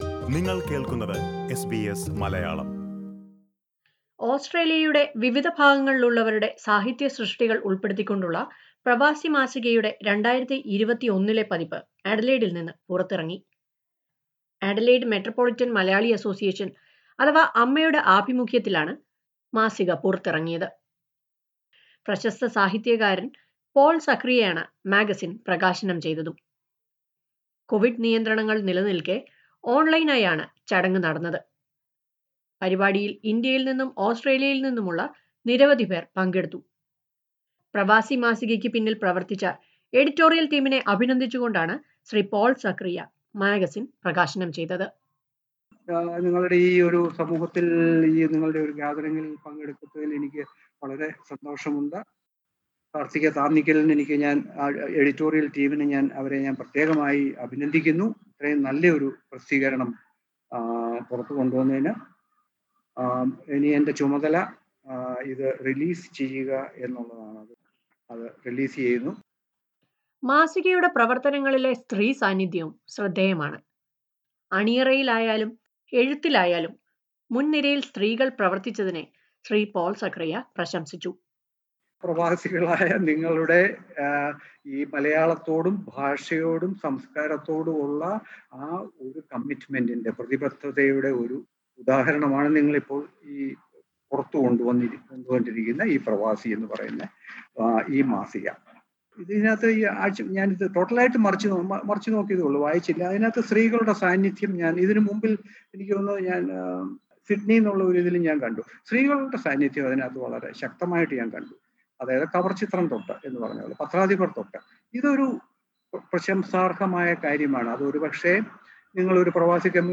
Pravasi a Malayalam magazine, an initiative of Adelaide Metropolitan Malayali Association has been launched by famous writer Paul Zachariah. Listen to a report on the launching event of this digital magazine.